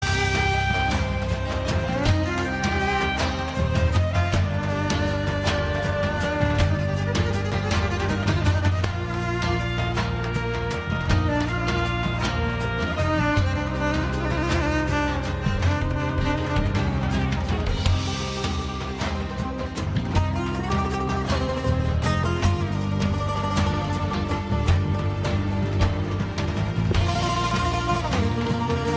Categoria Giochi